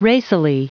Prononciation du mot racily en anglais (fichier audio)
Prononciation du mot : racily